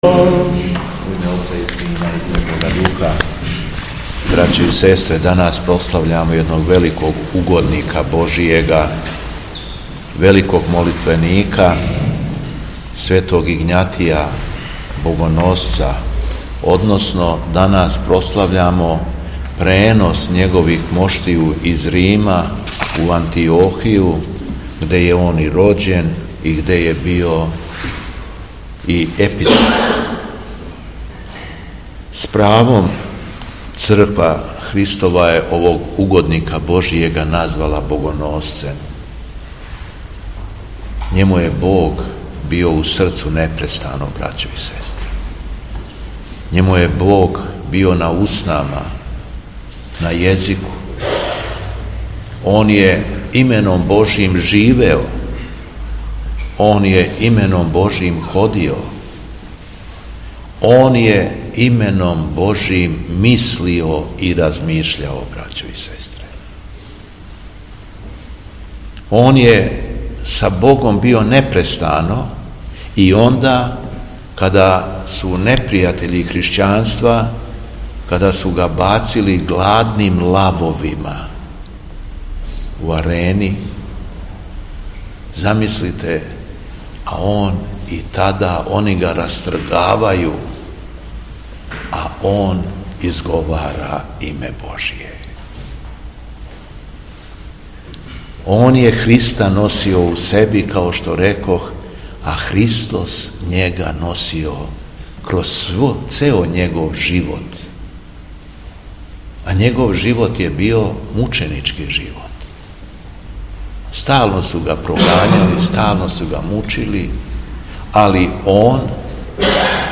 Беседа Његовог Високопреосвештенства Митрополита шумадијског г. Јована
Након прочитаног јеванђељског штива верном народу се надахнутом беседом обратио Митрополит Јован: